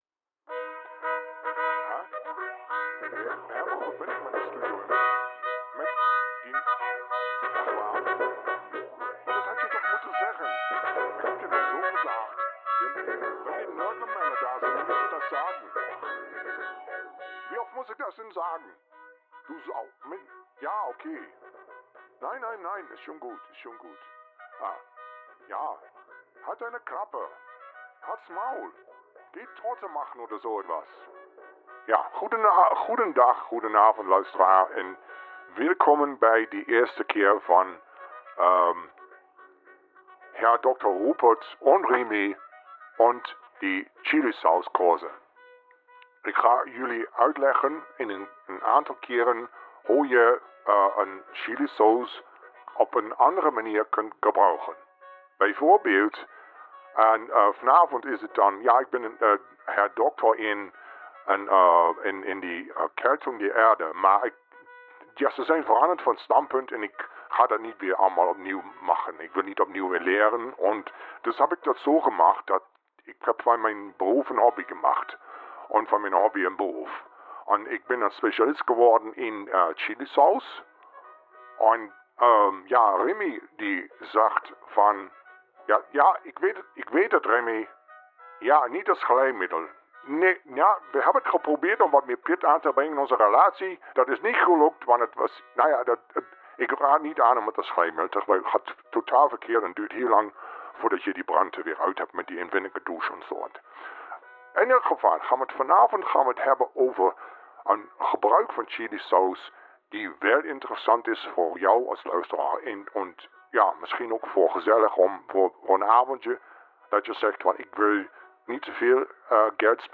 Uitzending van donderdag 29 juni 2023.
Moppermannen is een radio programma dat eens in de drie weken LIVE wordt uitgezonden op Oldambt FM. Naast de bierproefavond die als een rode draad door de uitzending loopt is er ook voldoende muziek te horen.